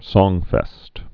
(sôngfĕst, sŏng-)